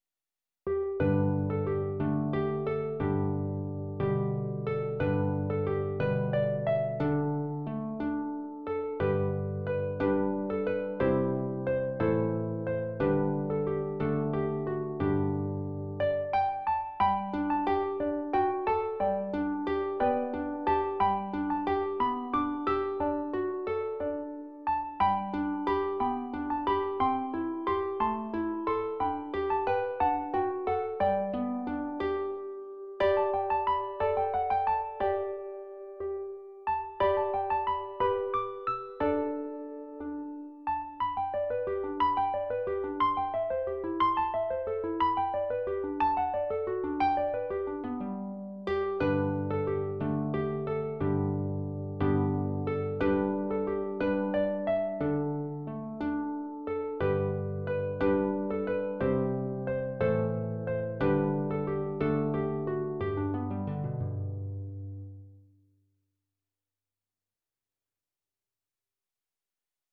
Arranged for solo lever or pedal harp
Christmas carols